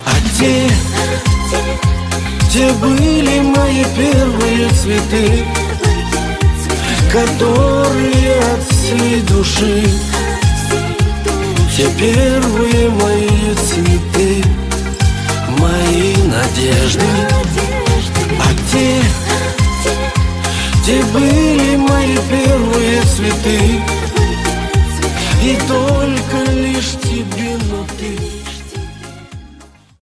• Качество: 128, Stereo
мужской вокал